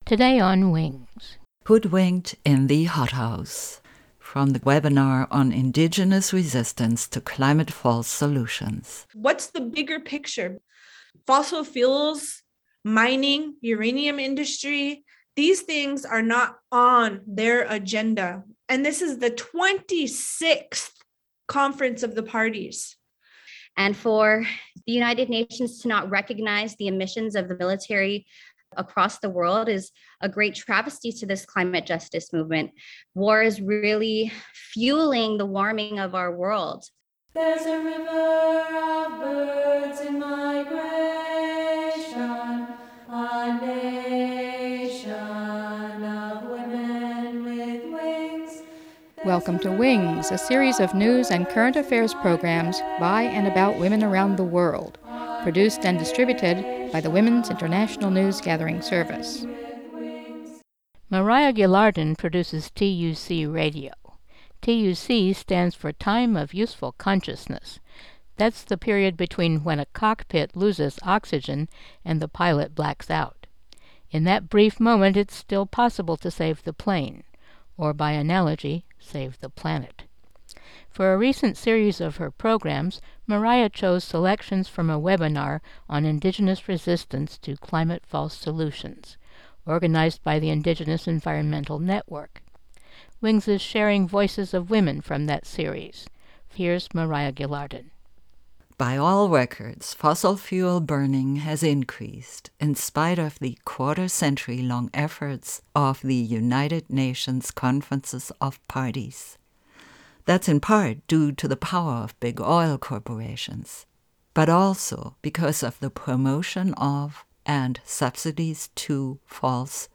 Mono
indigenous speakers